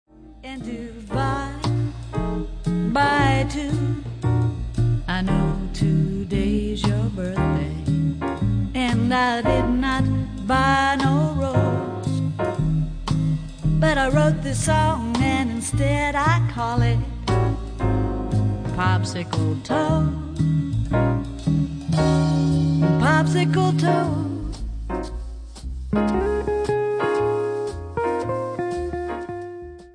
NOTE: Background Tracks 1 Thru 10